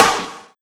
SNARE113.wav